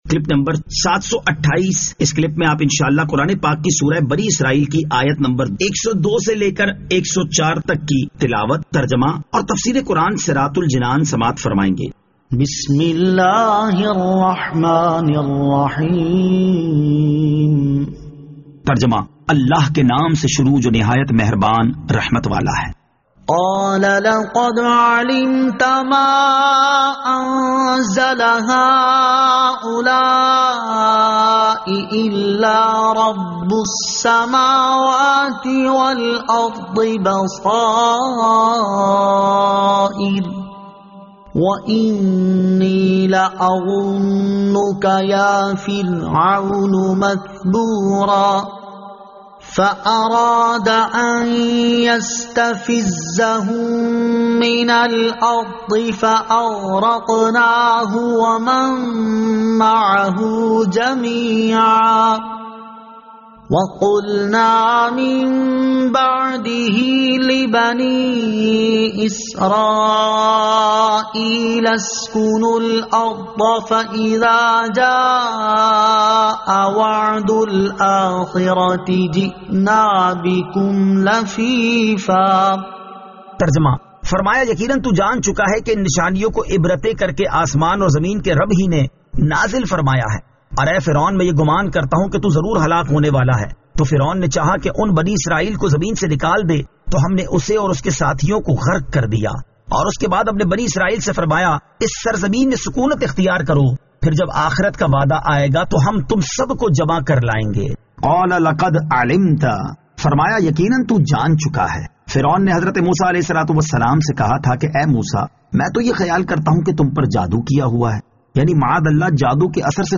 Surah Al-Isra Ayat 102 To 104 Tilawat , Tarjama , Tafseer